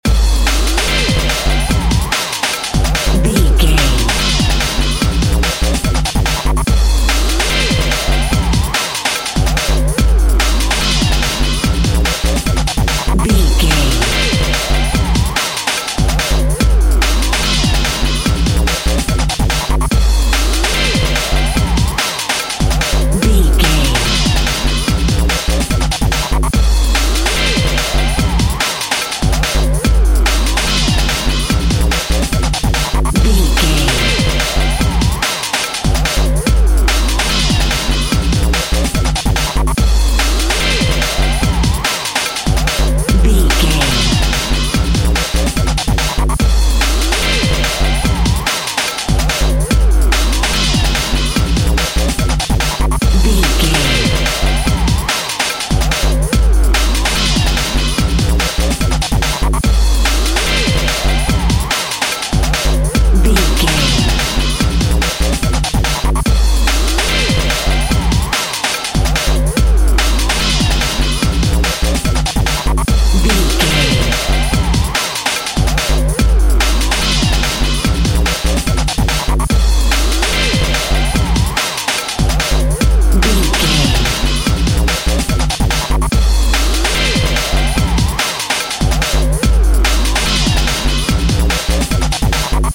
Epic / Action
Fast paced
Atonal
Fast
intense
futuristic
energetic
driving
repetitive
aggressive
dark
synthesiser
drum machine
breakbeat
pumped up rock
synth leads
synth bass